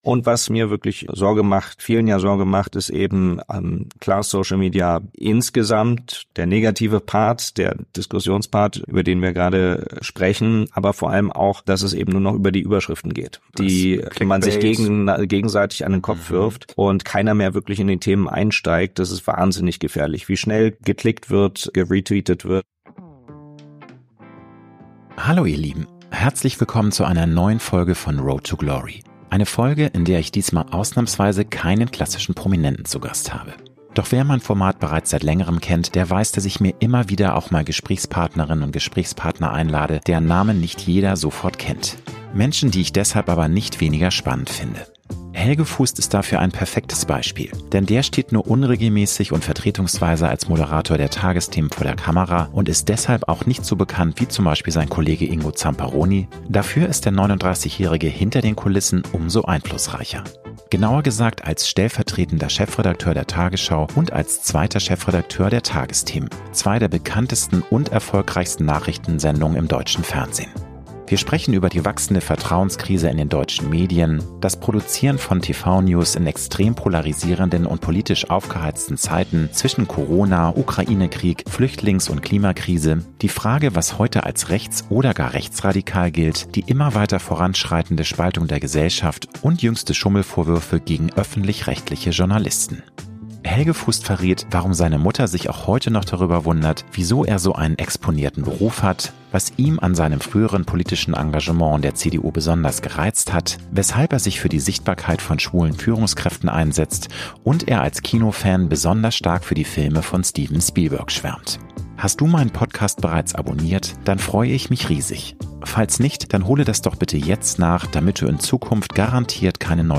119. Helge Fuhst: Vom Nachrichten machen in extrem polarisierten Zeiten ~ Road to Glory - Promi-Talk